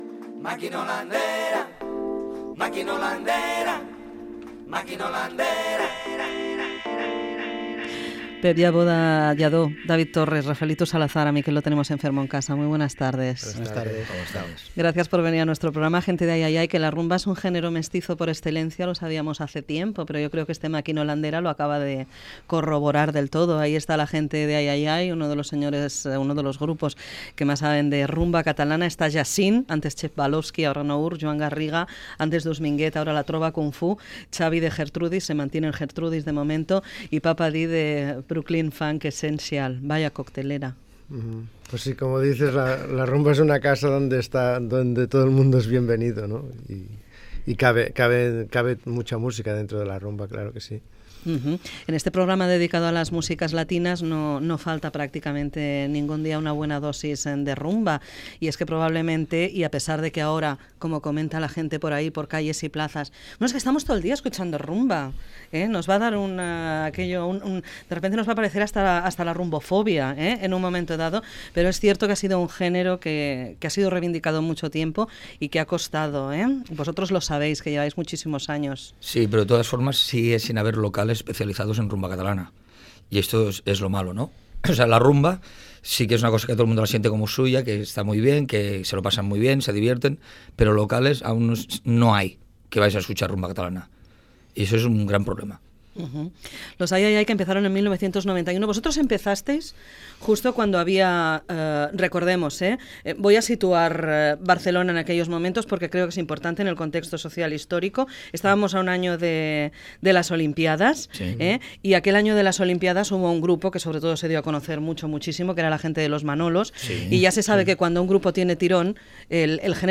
2e63c1bb07811b12cad4e0718ef51449eaf57b77.mp3 Títol Radio 3 Emissora Radio 3 Cadena RNE Titularitat Pública estatal Nom programa Pachamama Descripció Fragment del programa dedicat al grup de rumba "Ai Ai Ai". Gènere radiofònic Musical